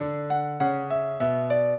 piano
minuet4-7.wav